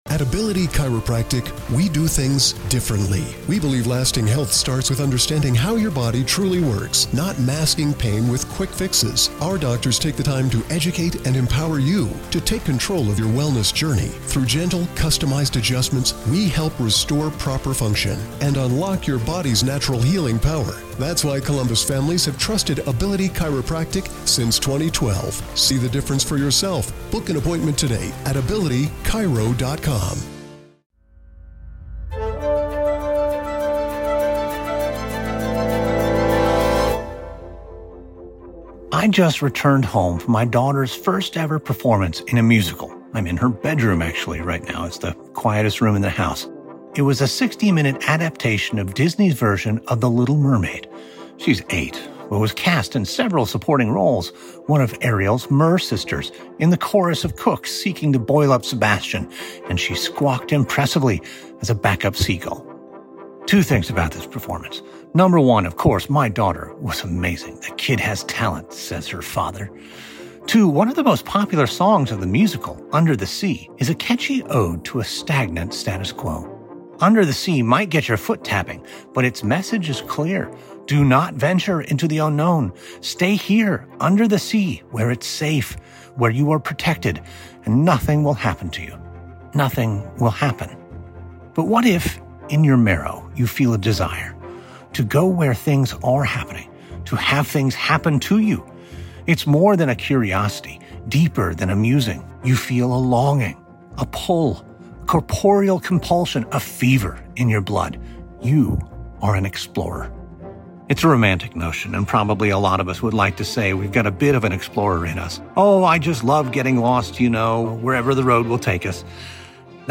On today’s Saturday Matinee: The Explorers Podcast is a historical narrative podcast about famous explorers and explorations from throughout history. The show takes listeners across oceans, into deep jungles, to the top of mountains, on to the polar ice caps, into outer space, and along the world’s greatest rivers.